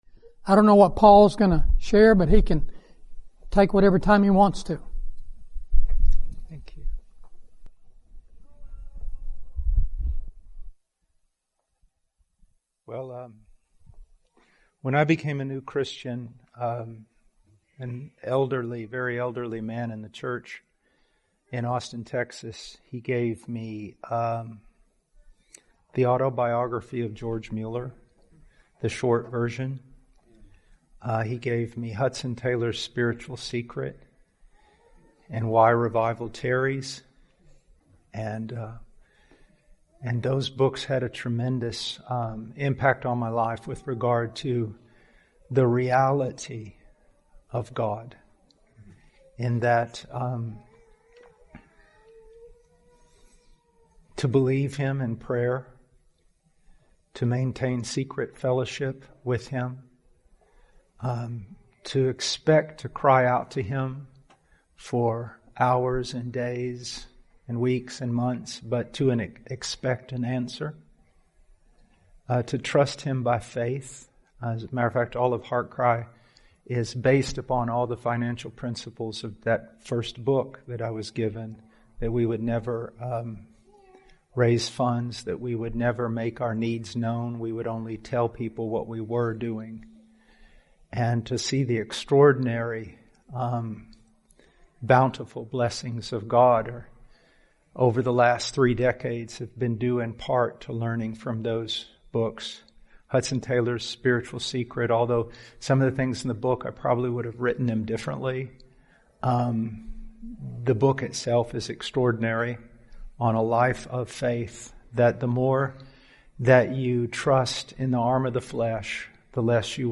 Category: Questions & Answers
Book Table Tour from the 2021 Fellowship Conference , held yearly in Denton, Texas.